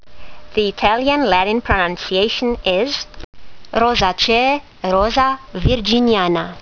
Listen to the Latin Print a QR link to this factsheet symbol: ROVI2